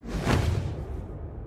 knockback.mp3